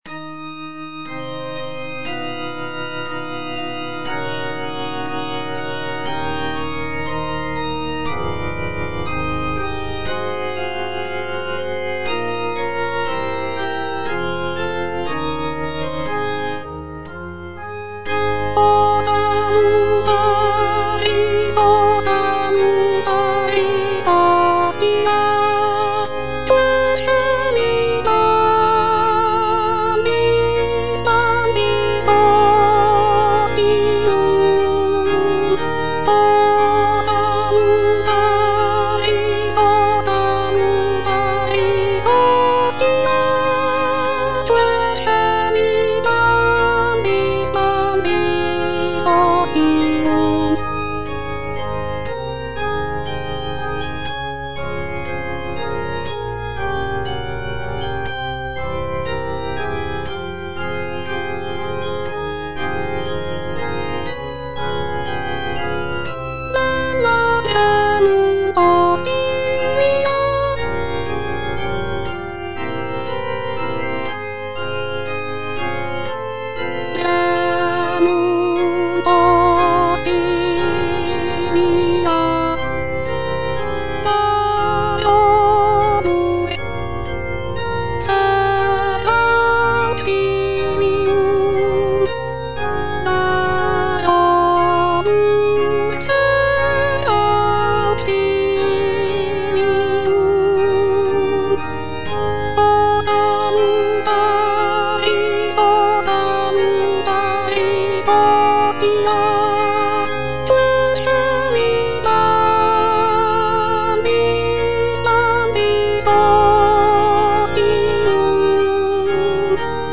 G. FAURÉ, A. MESSAGER - MESSE DES PÊCHEURS DE VILLERVILLE O salutaris (soprano II) (Voice with metronome) Ads stop: auto-stop
The composition is a short and simple mass setting, featuring delicate melodies and lush harmonies.